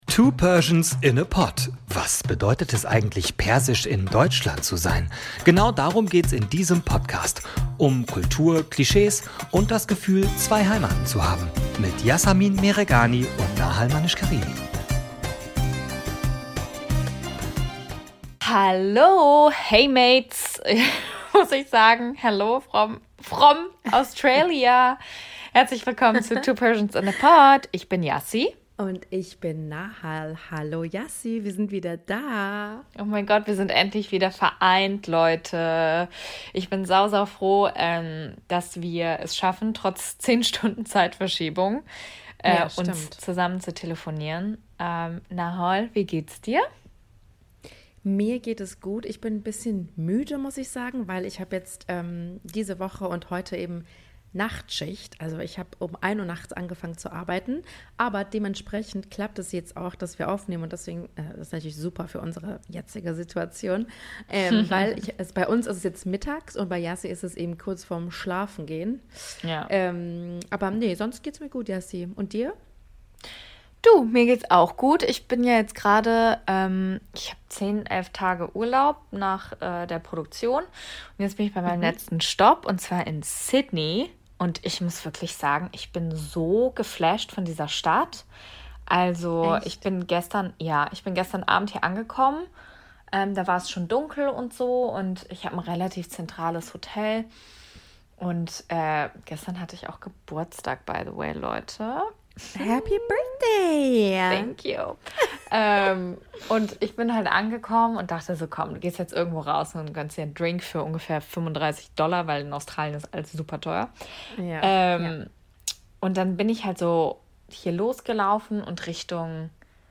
In dieser Folge live aus Sydney geht's um all das, was wir vielleicht nicht direkt mit Australien assoziieren. Es geht um die Geschichte, die Kolonialisierung und Geschichte der Anangu.